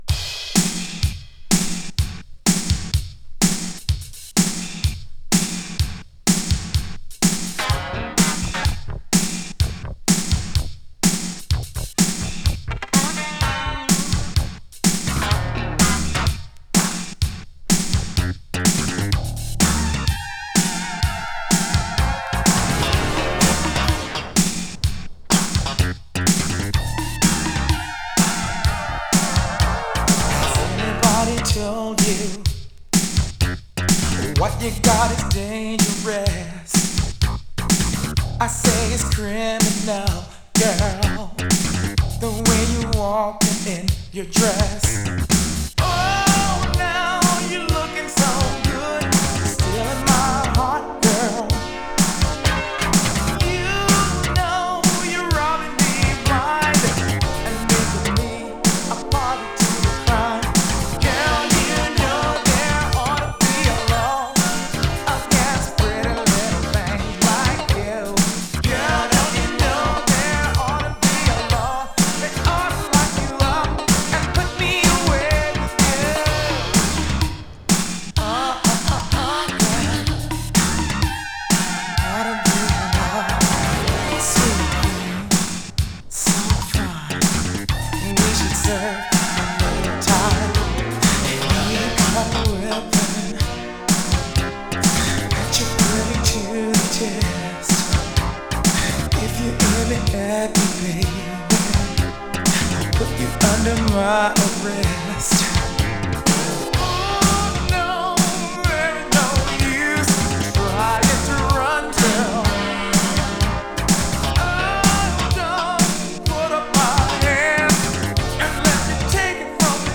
Indie Boogie from L.A.!
ファンキーなベースラインが印象的な好シンセ・ブギー！